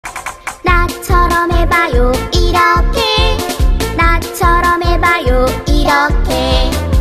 나 처럼 해봐요 (Korean Nursery Rhythm)